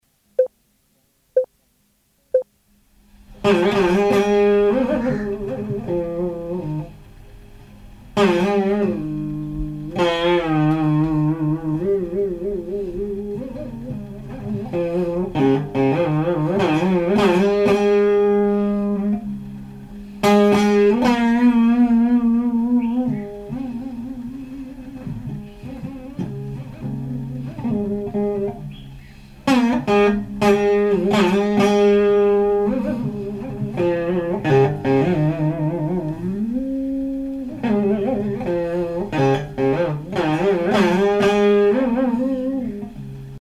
Musique carnatique
Pièce musicale inédite